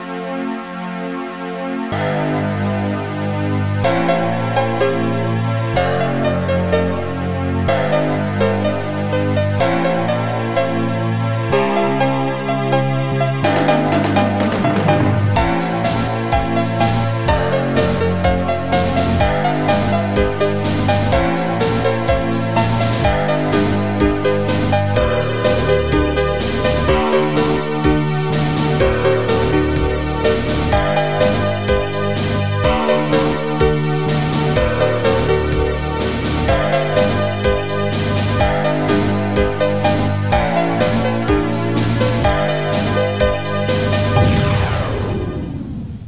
closed hihat 02
open hihat
Pluck
looping bass
big bass 01
Piano